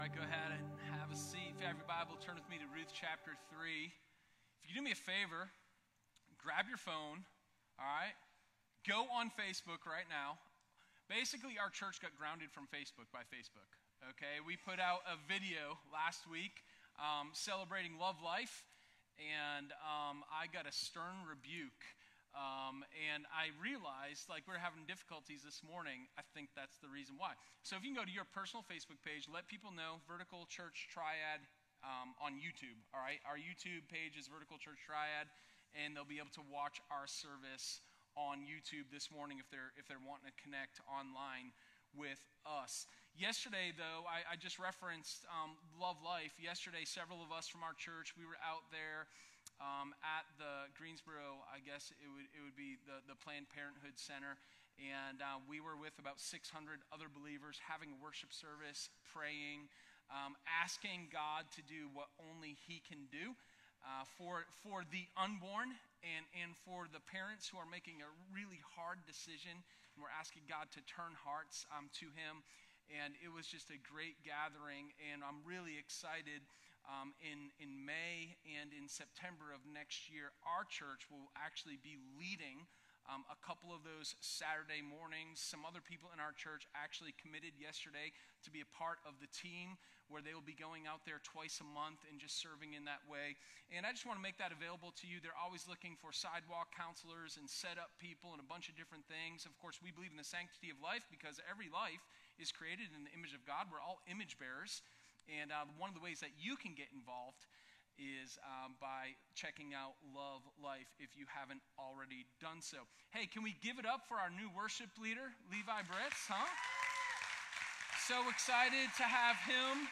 Sermon1122_Pledge-Your-Honor-to-the-Redeemer.m4a